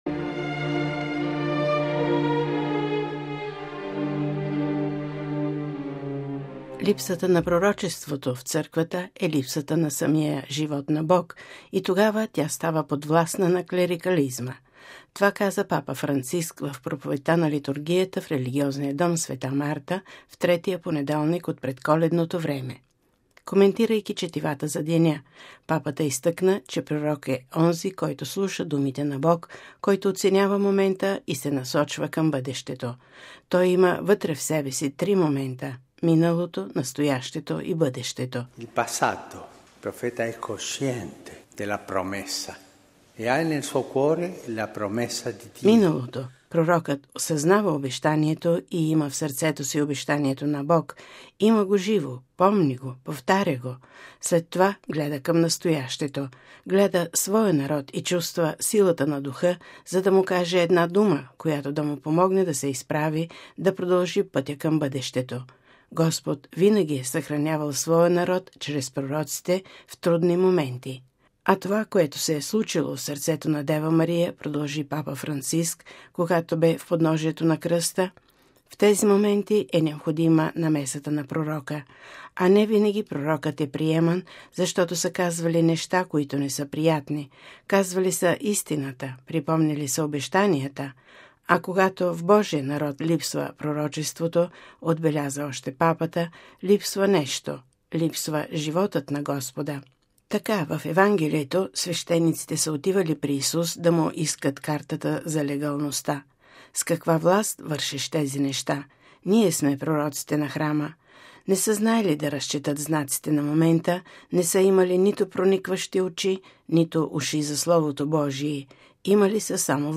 Това каза Папа Франциск в проповедта на литургията в религиозния Дом Света Марта в третия понеделник от Предколедното време.